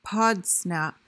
PRONUNCIATION: (POD-snap) MEANING: noun: A smug, self-satisfied person.